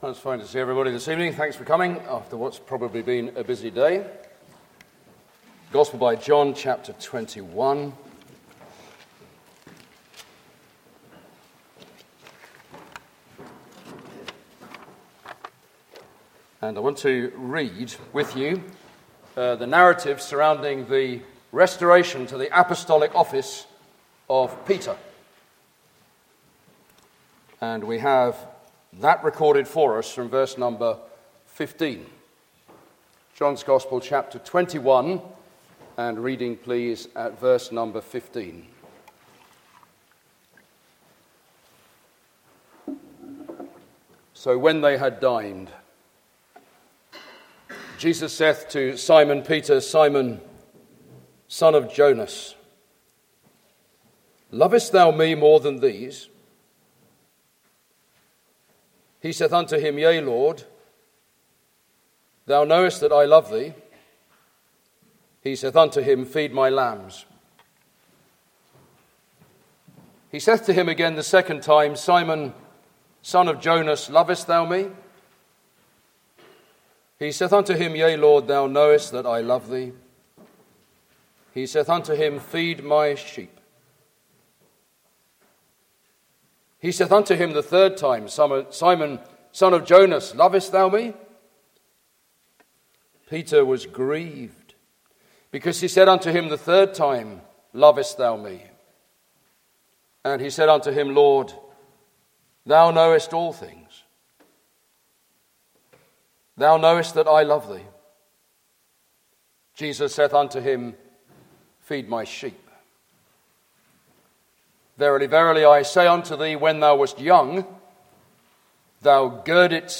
Various Practical Messages